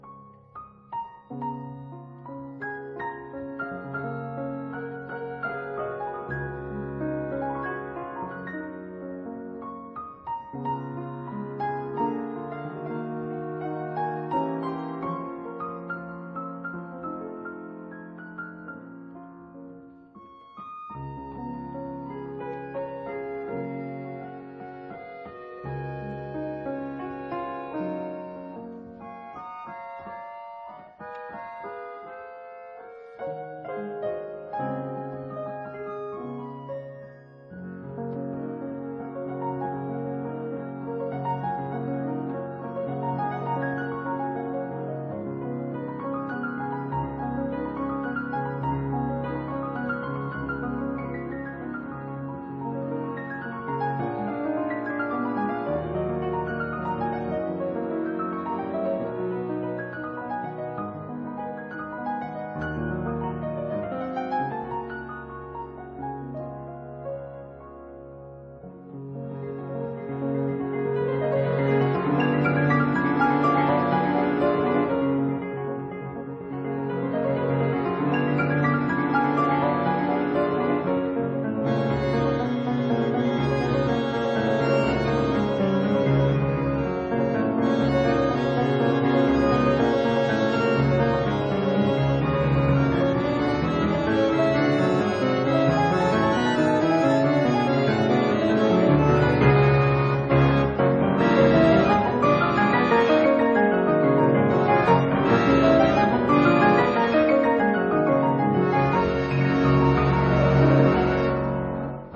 這是寫給簧風琴與鋼琴的曲子。
鋼琴提供節奏和精湛的細節，簧風琴則讓聲音有了廣度，
錄音使用的樂器包括一架1889年的架美麗簧風琴，
以及，聲音聽來高貴的1902年的Érard三角鋼琴。